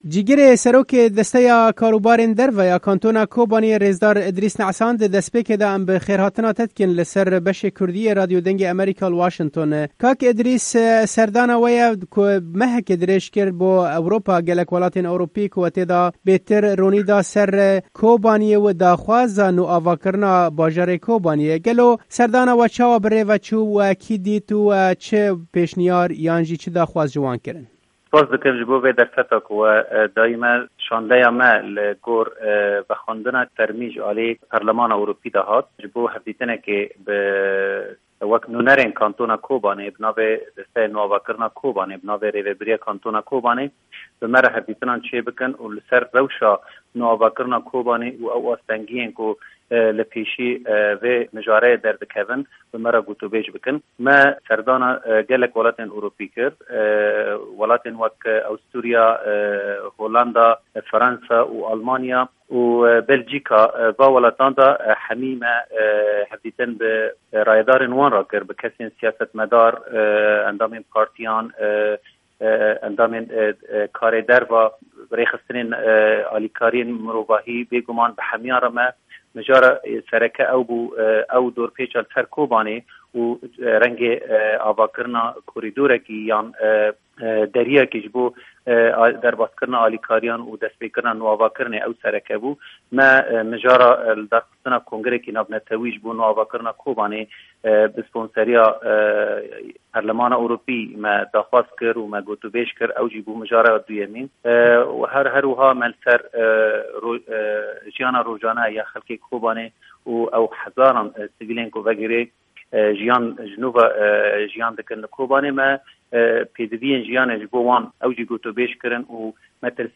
Ji bo guhdarîkirina li hevpeyvînê, faylê deng